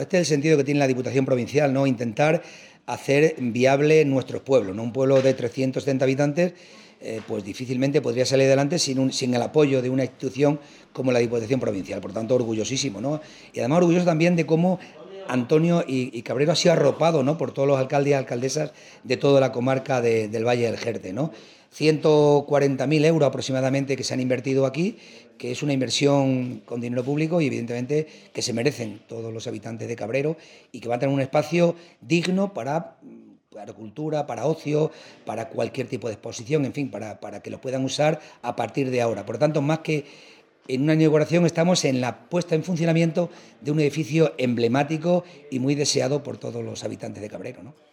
CORTES DE VOZ
Miguel Ángel Morales_Presidente Diputación_Centro de Interpretación 1